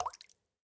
drip_water_cauldron3.ogg